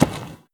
pedology_turf_footstep.2.ogg